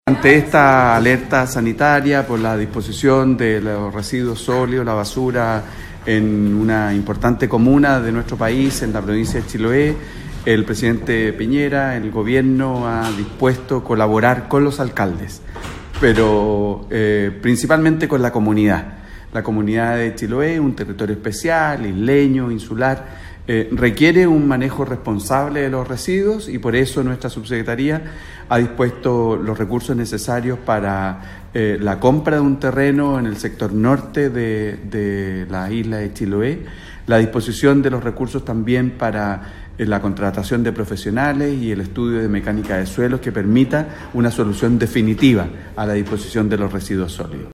El subsecretario Felipe Salaberry explicó en que se tienen que invertir los recursos.
CUÑA-FELIPE-SALABERRY-.mp3